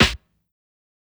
SNARE_SWIG.wav